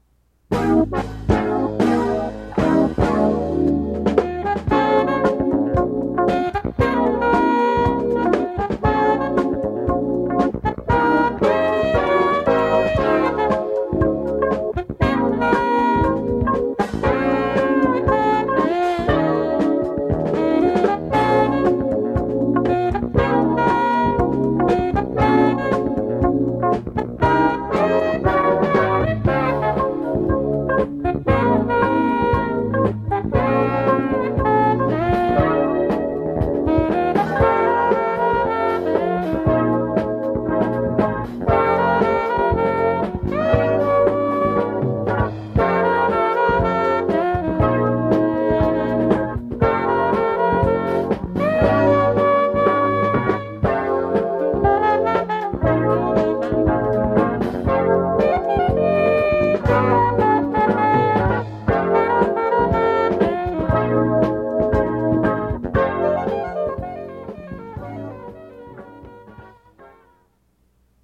audio cassette
reformatted digital